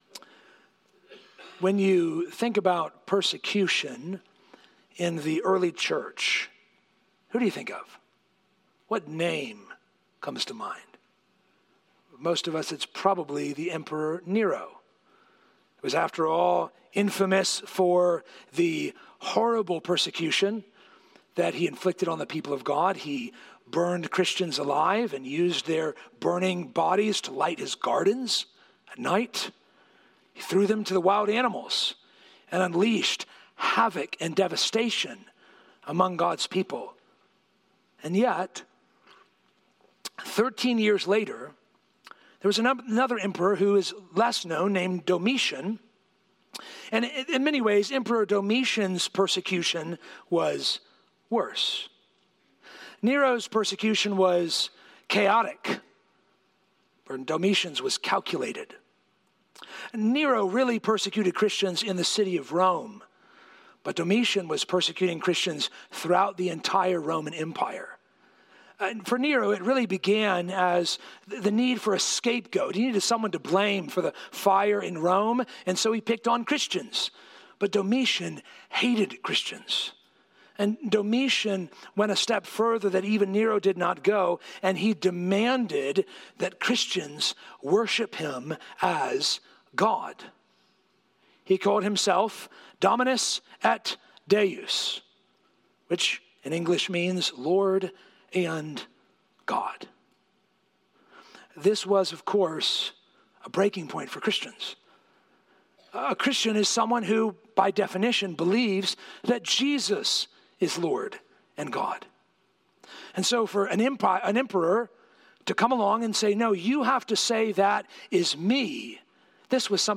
This is the Sermon podcast of Poquoson Baptist Church